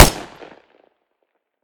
smg-shot-01.ogg